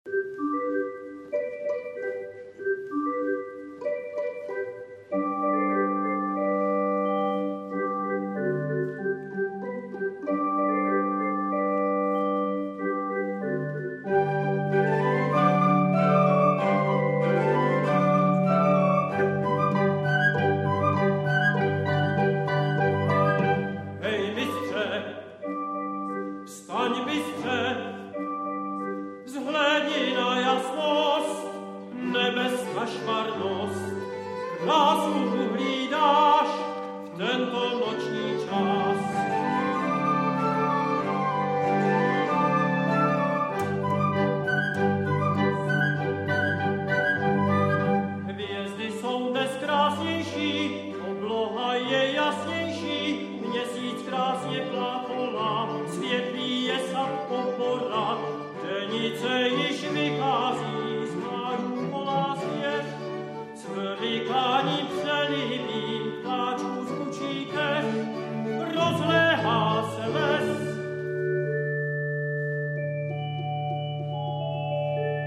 ukázka záznamu koncertu -